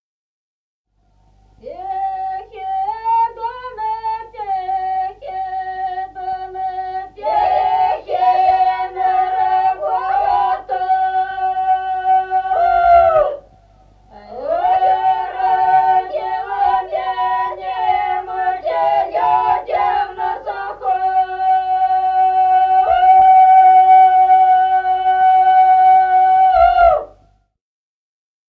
Песни села Остроглядово. Тихий Дунай (петровочная).